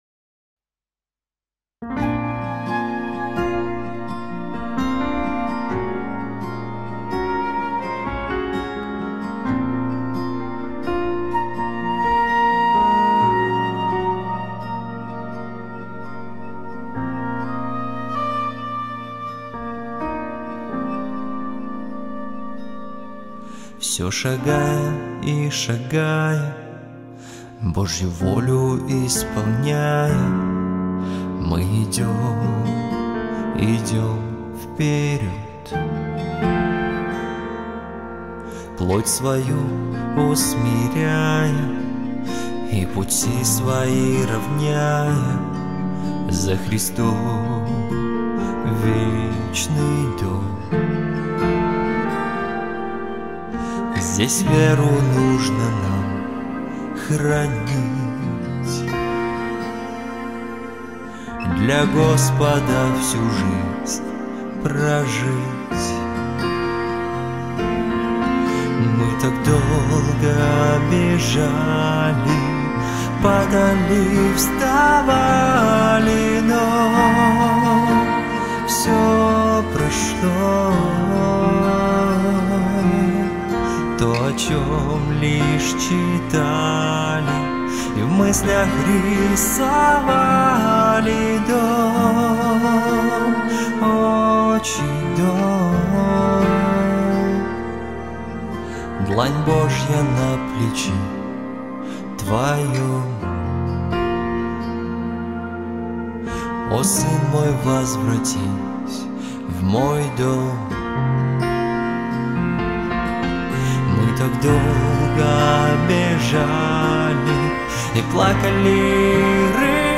песня
237 просмотров 110 прослушиваний 16 скачиваний BPM: 64